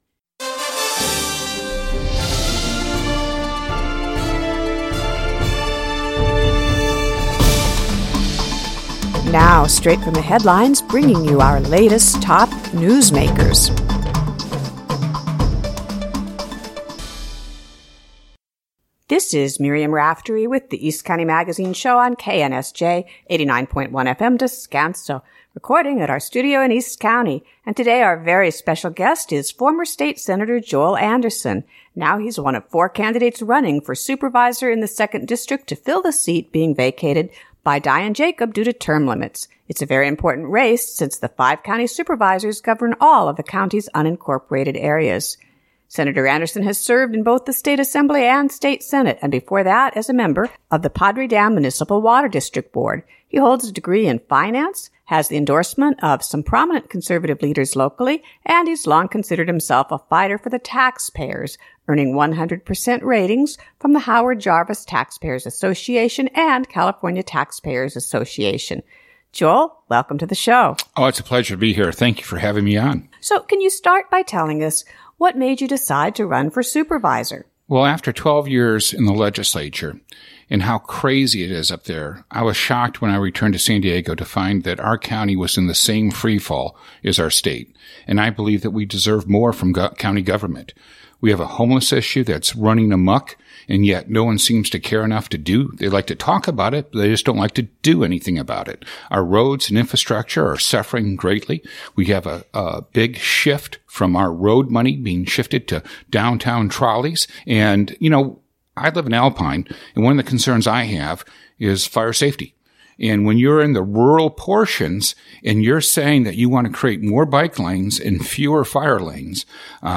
Full audio recordings are now posted below, along with highlights of candidates' thoughtful responses.